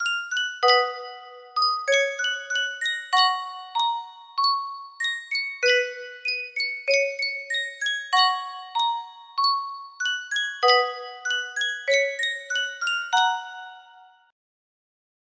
Sankyo Music Box - Sakura EUG music box melody
Full range 60